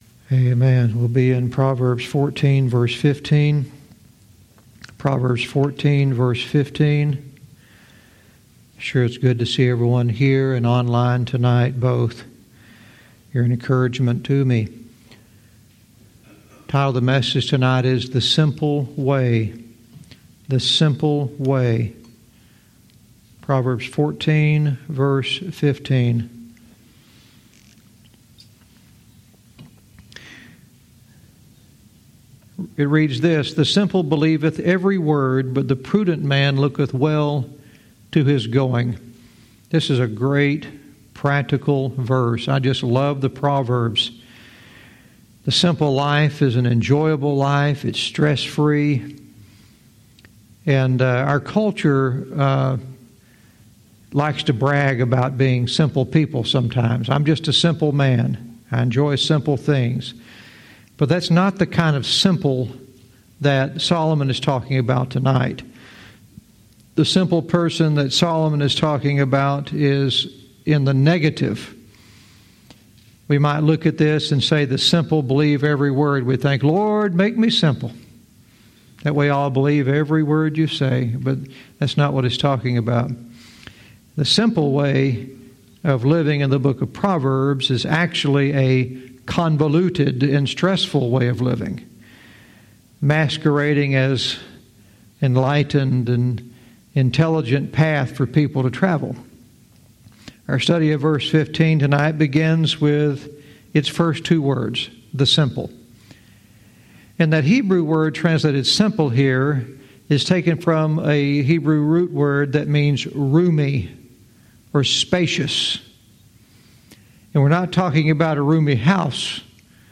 Verse by verse teaching - Proverbs 14:15 "The Simple Way"